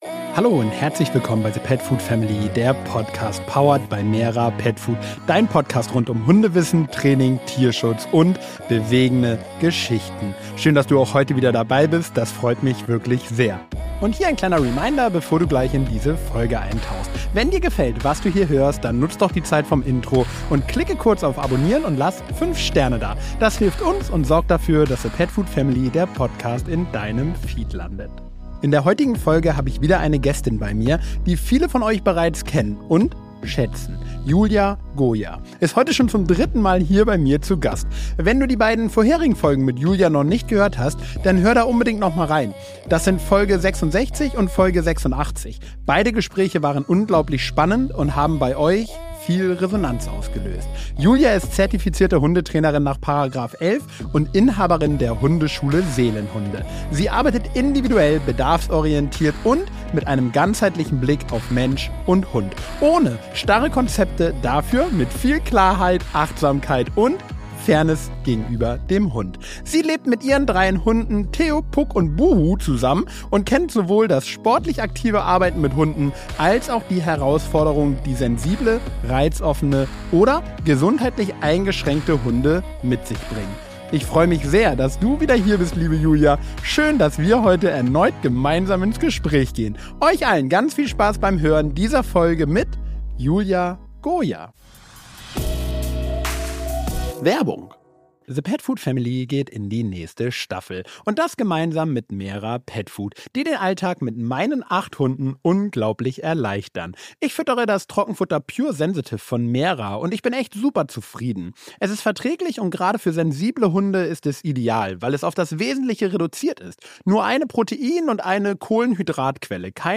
Im Gespräch mit Hundetrainerin